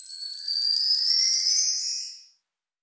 Dilla Chime 02.wav